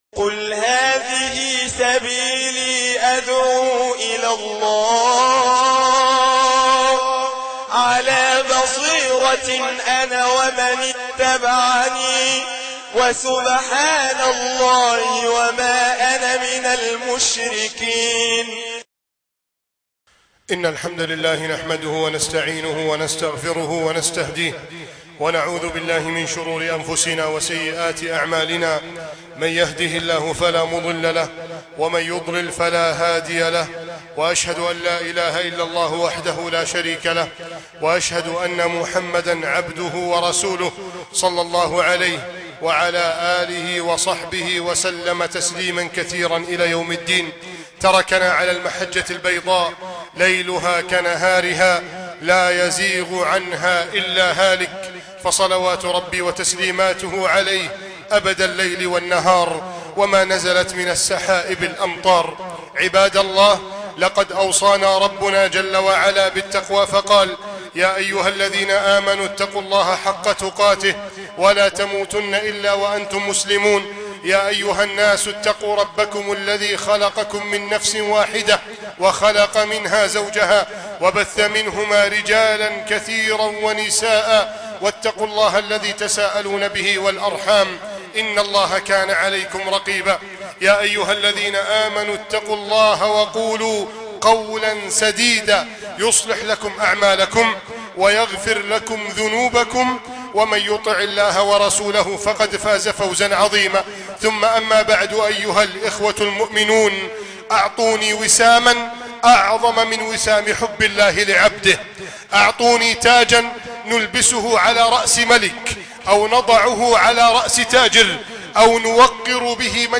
فإذا أحببته ( خطبة الجمعة ) مسجد التابعين - بنها